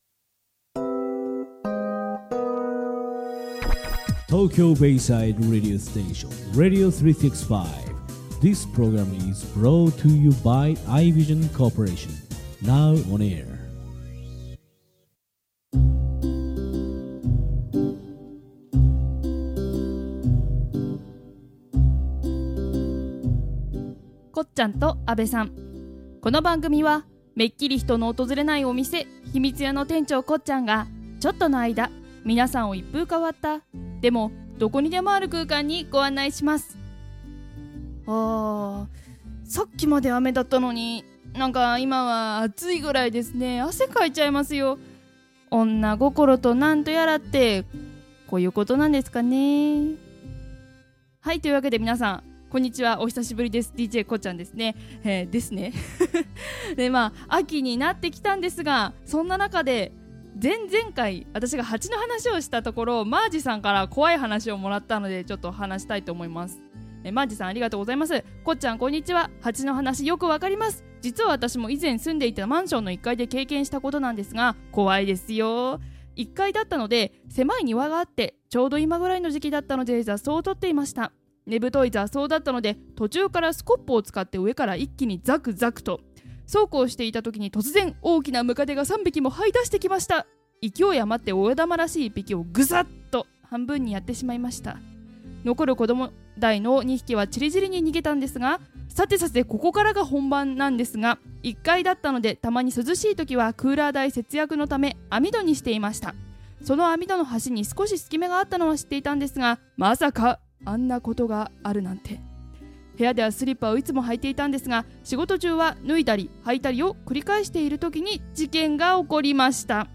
局長による神編集のおかげで事なきを得ているはずですが、収録中「カミカミの神様」に愛されてしまいました。（笑）